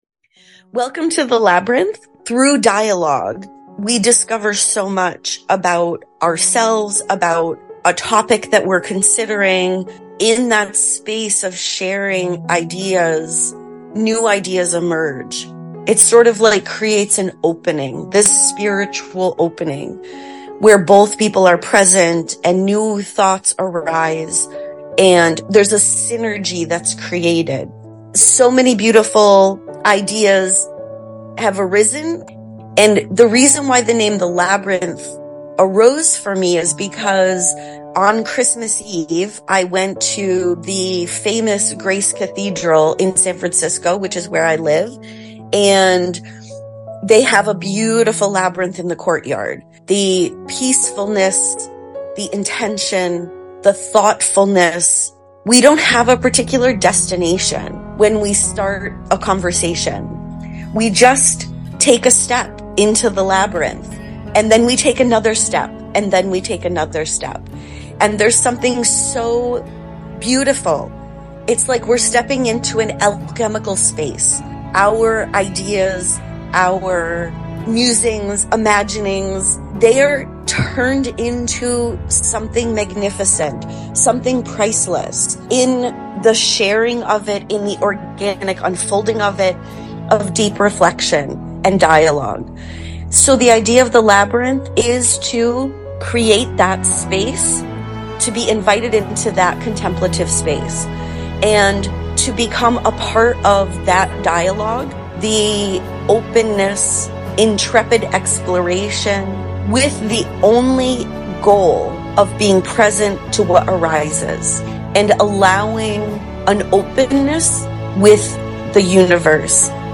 The Labyrinth, A Trailer
2015; 'The Sound of Silence' (Instrumental); Immortalized; Reprise Records